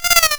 raindrop_placeholder.ogg